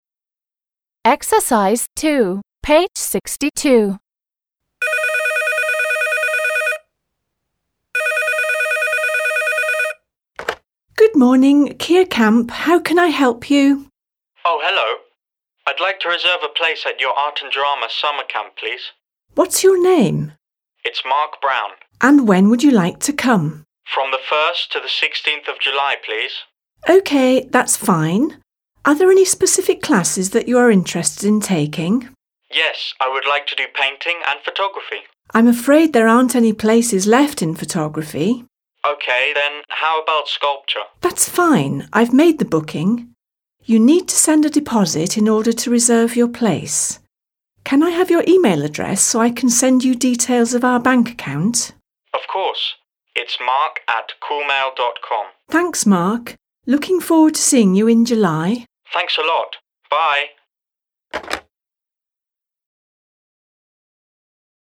2. The sentences above are from a dialogue between a teenager and a receptionist at a camp. Who says each sentence? Listen and check. − Предложения выше из диалога между подростком и администратором в лагере. Кто произносит каждое предложение? Послушайте и проверьте.